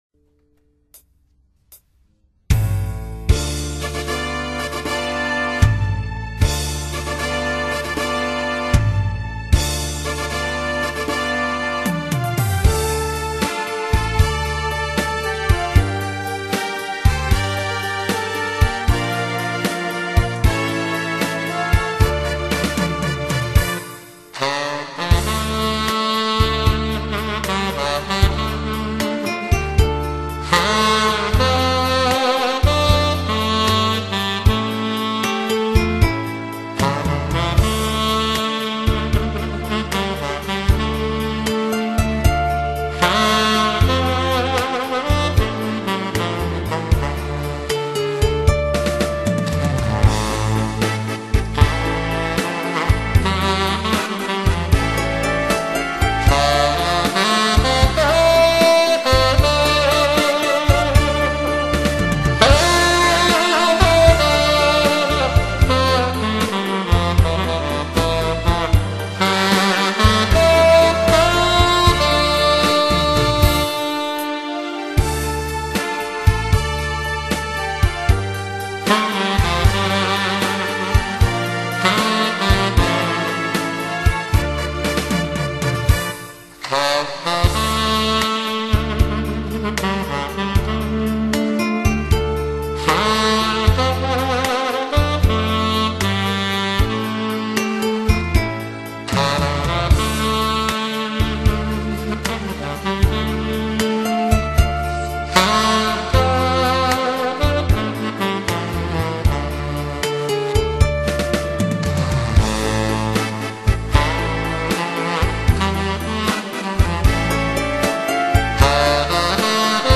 이 연세에도 파워풀하게 연주를 할 수 있다는게 믿기지 않을 정도입니다.